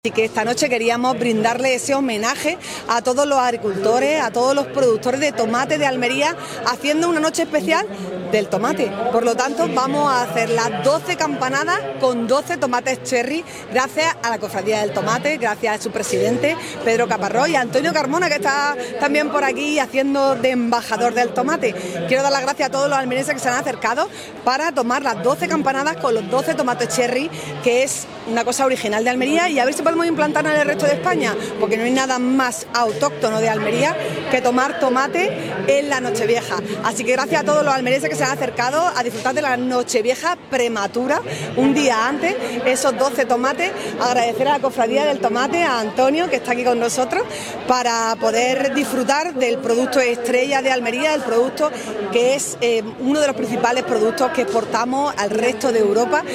CORTE-ALCALDESA-TOMATES.mp3